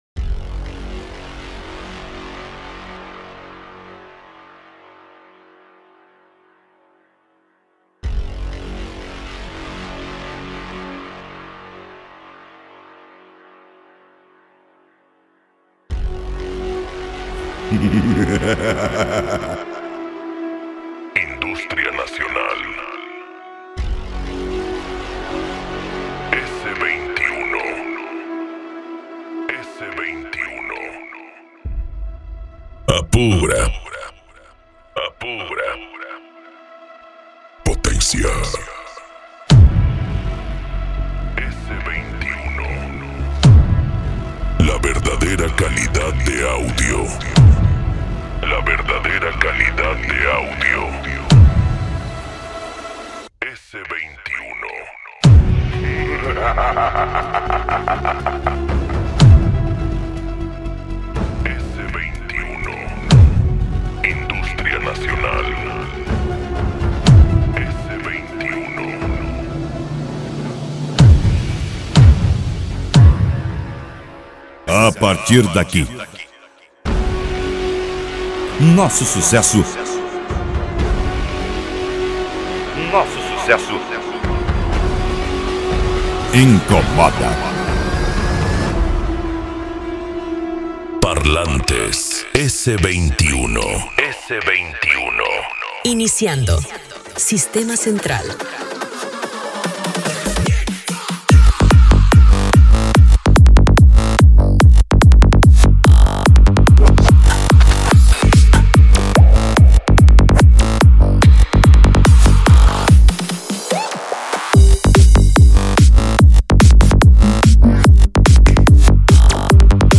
Eletronica
PANCADÃO
Psy Trance
Remix